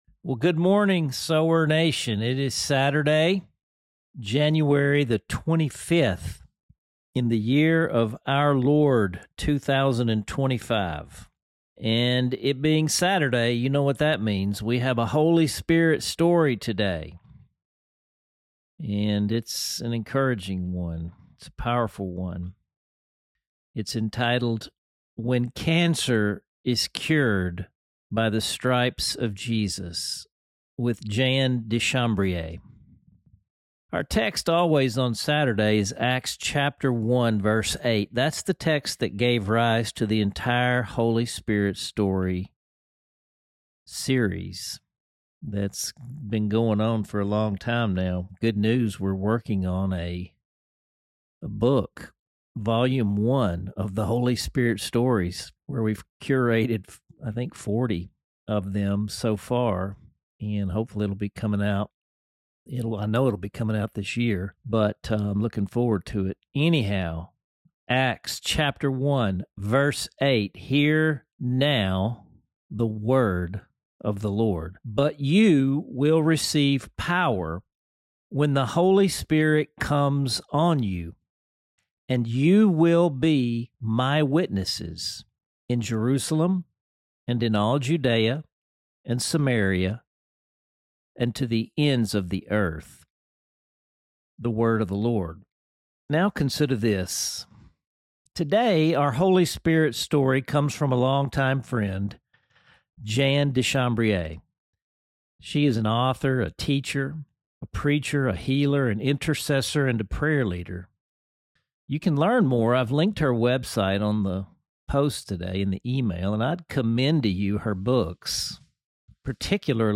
(John 14:12–14) Below, you will read the story in her words, and, if you listen, you will hear it in her own voice.